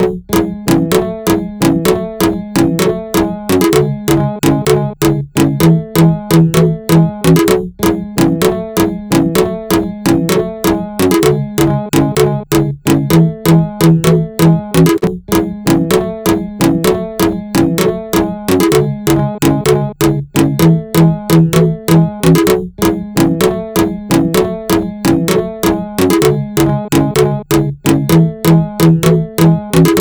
インストゥルメンタルエレクトロニカショート